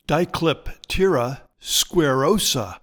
Pronounciation:
Di-clip-TEAR-a square-OO-sa